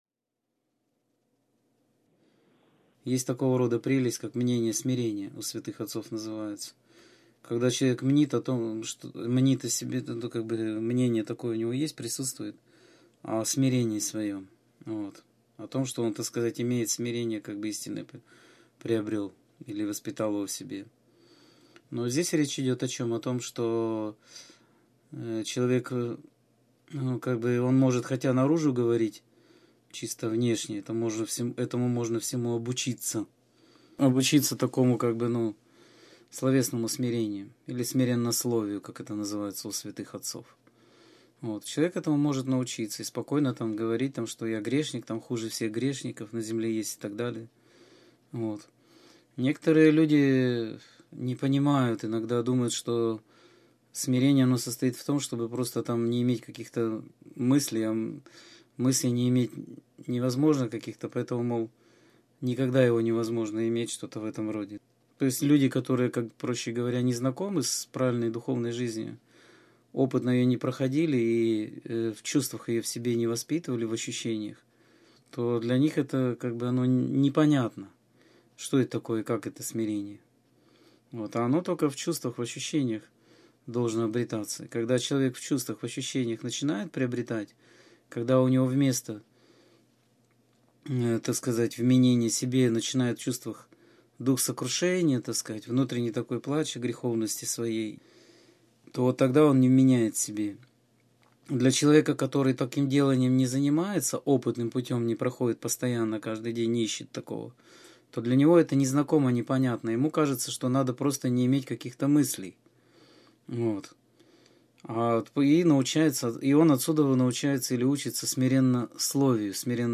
Скайп-беседа 15.03.2014